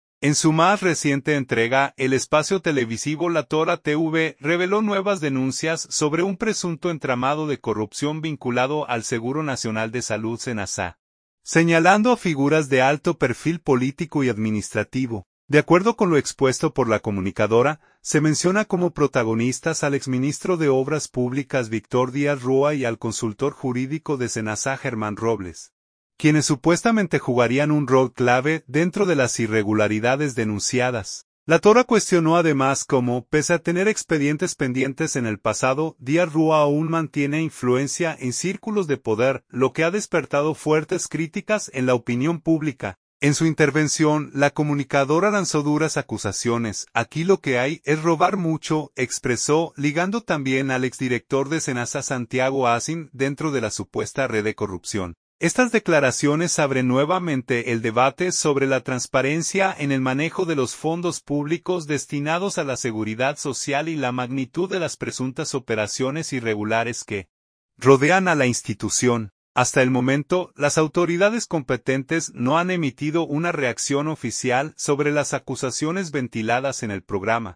En su intervención, la comunicadora lanzó duras acusaciones: